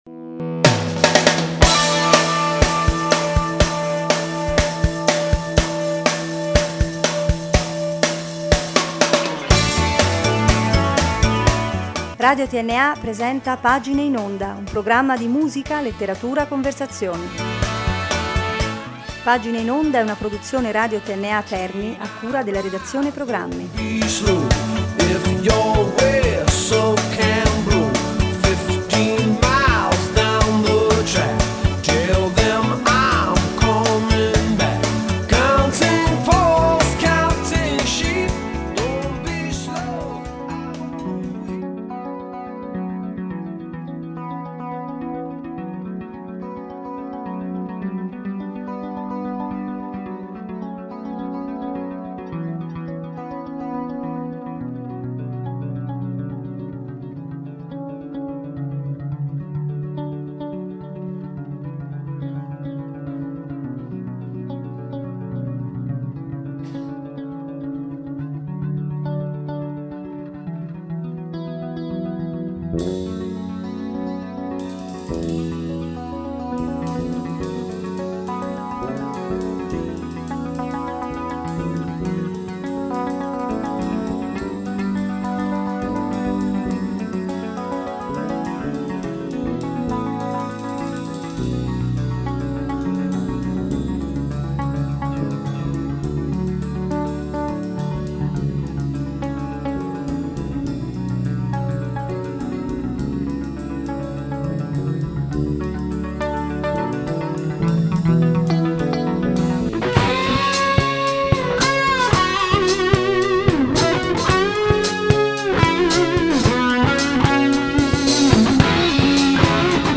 Sabato 8 novembre – Cenacolo San Marco